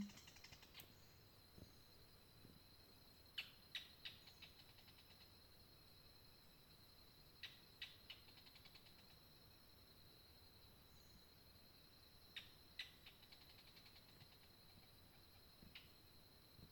Canebrake Groundcreeper (Clibanornis dendrocolaptoides)
Province / Department: Misiones
Location or protected area: Reserva Papel Misionero
Condition: Wild
Certainty: Observed, Recorded vocal